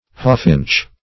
Hawfinch \Haw"finch`\ (h[add]"f[i^]nch`), n. (Zool.)